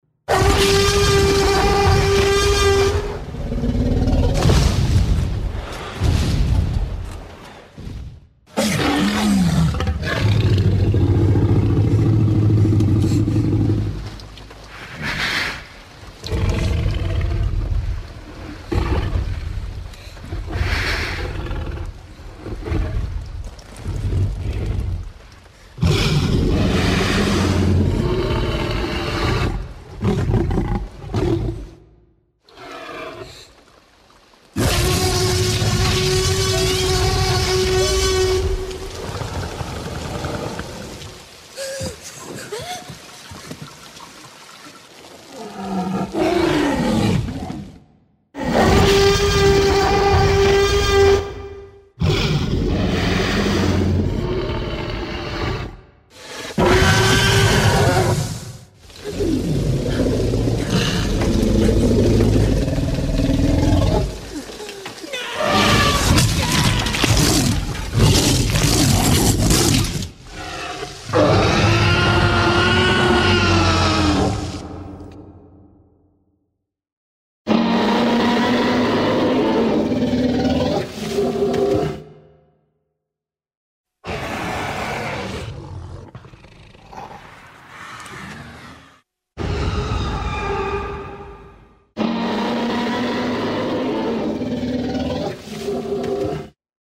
Звук динозавра из фильма «Юрский Период»